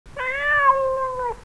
cat.mp3